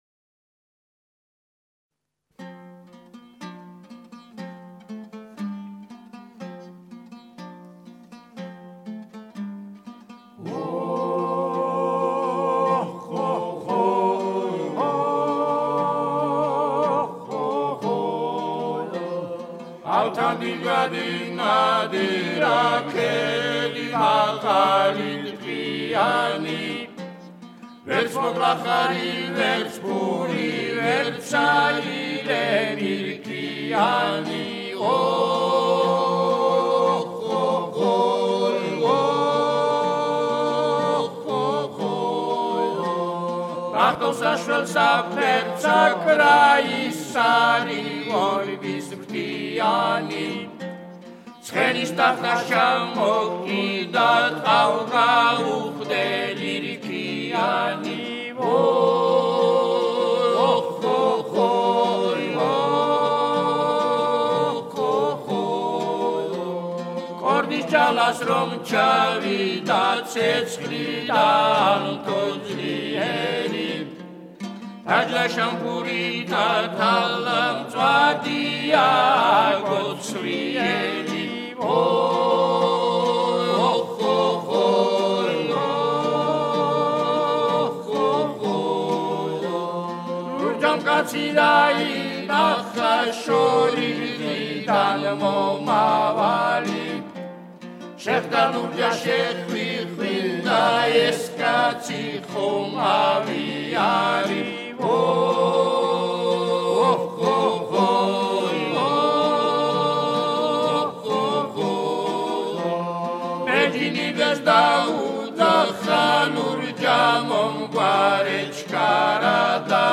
Georgian Folklore